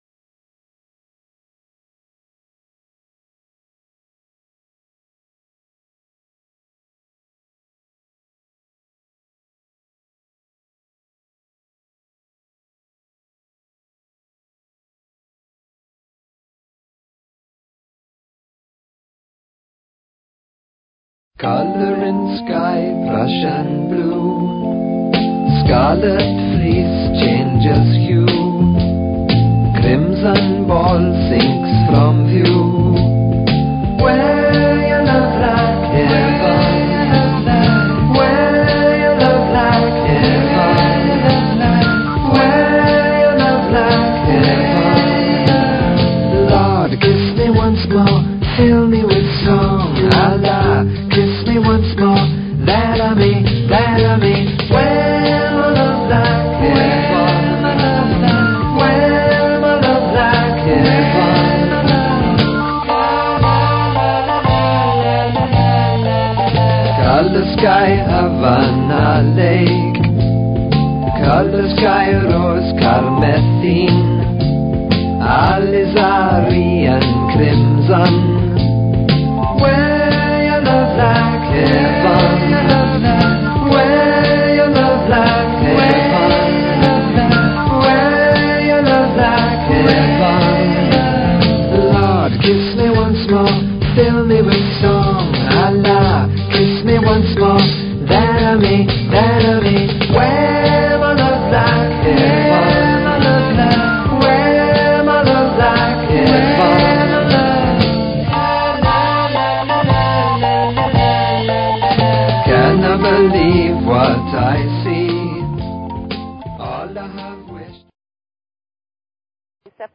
Talk Show Episode, Audio Podcast, Earth_Harmony_Divinations and Courtesy of BBS Radio on , show guests , about , categorized as